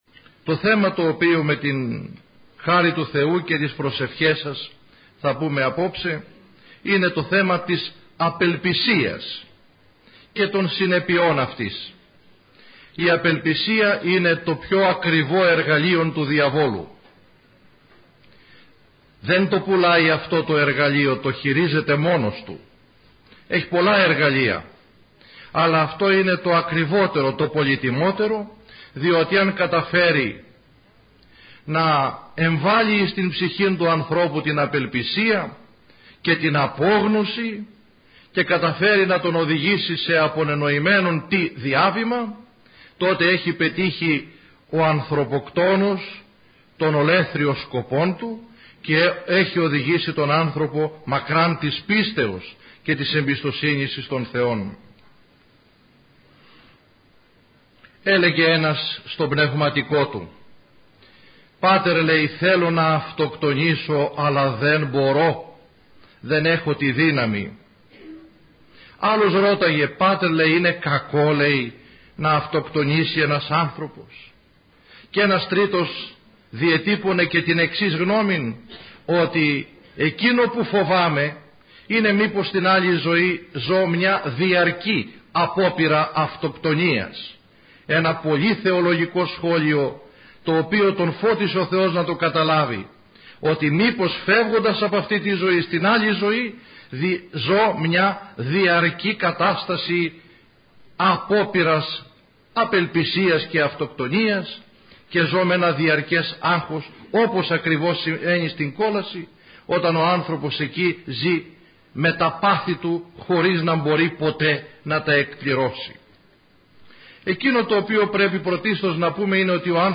Η ομιλία αυτή “πραγματοποιήθηκε” στην αίθουσα της Χριστιανικής ενώσεως Αγρινίου.